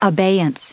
added pronounciation and merriam webster audio
939_abeyance.ogg